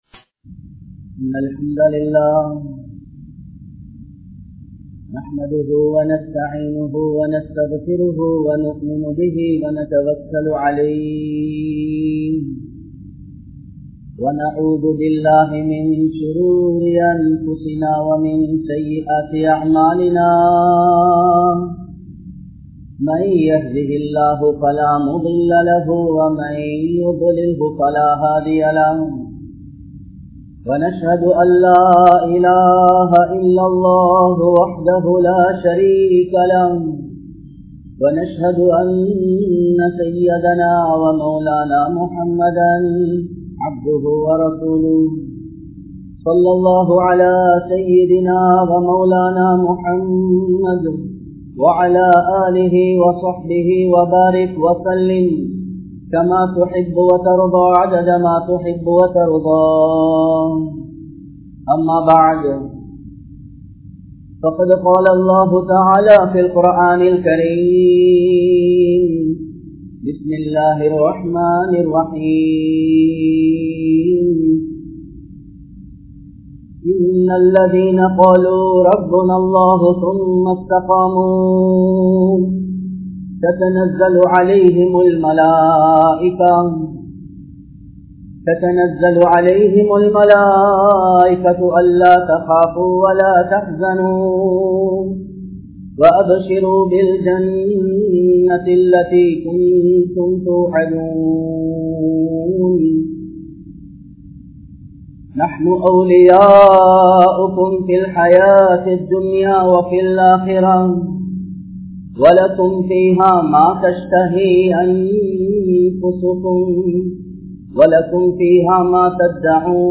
Amalhal Eattru Kolla Pattathan Adaiyaalangal (அமல்கள் ஏற்றுக் கொள்ளப்பட்டதன் அடையாளங்கள்) | Audio Bayans | All Ceylon Muslim Youth Community | Addalaichenai
Kollupitty Jumua Masjith